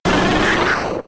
Cri de Coconfort K.O. dans Pokémon X et Y.